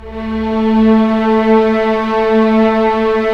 Index of /90_sSampleCDs/Roland LCDP13 String Sections/STR_Orchestral p/STR_Orch. p Slow